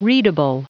Prononciation du mot readable en anglais (fichier audio)
Prononciation du mot : readable